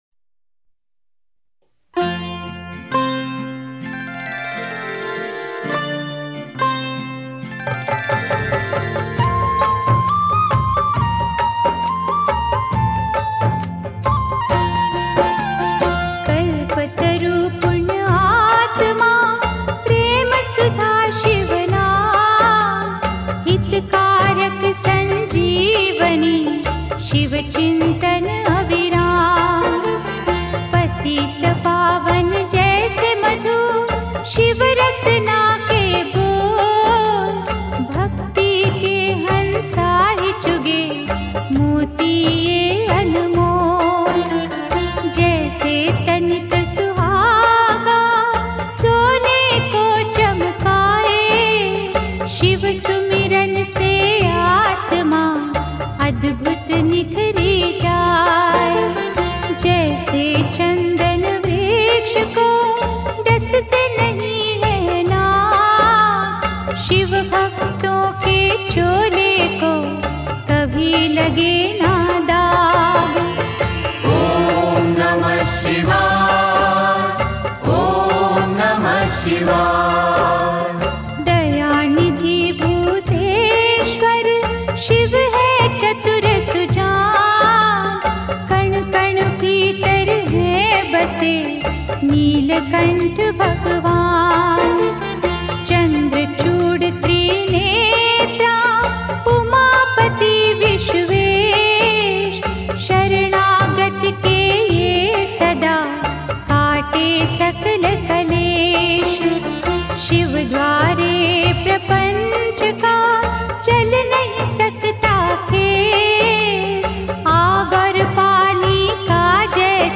Devotional Songs > Shiv (Bholenath) Bhajans